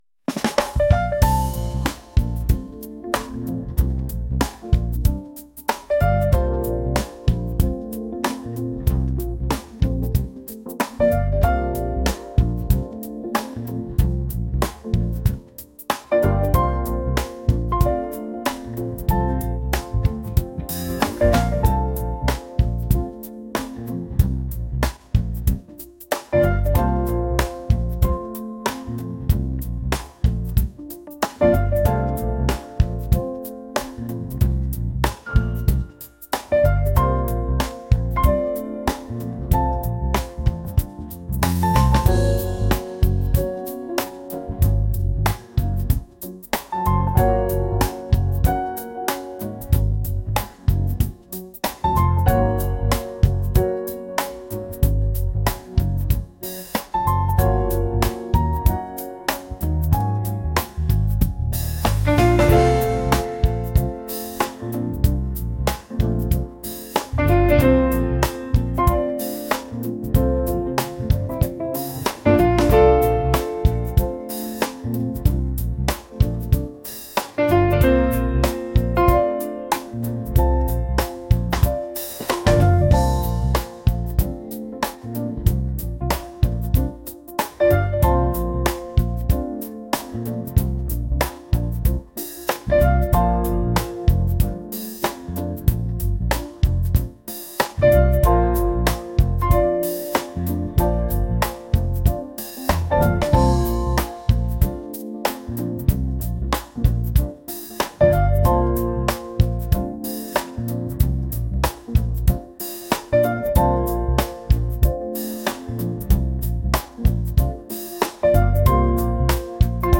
jazz | smooth | soulful